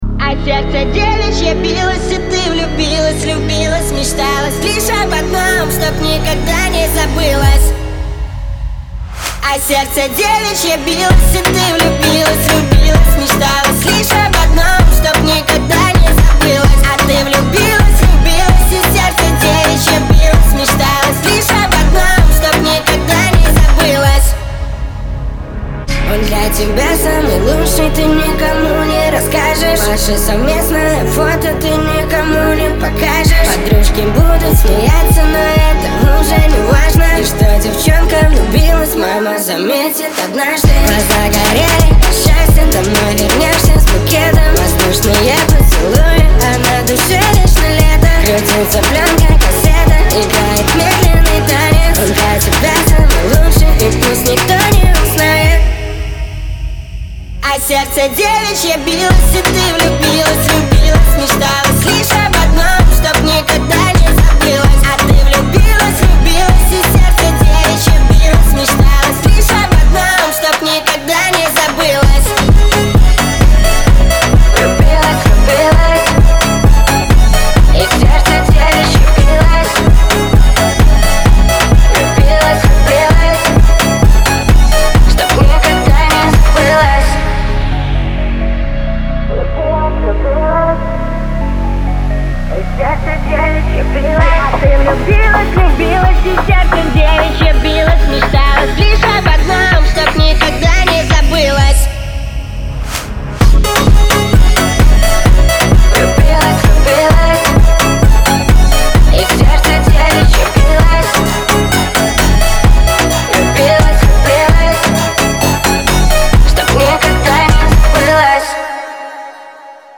pop , дуэт
эстрада
диско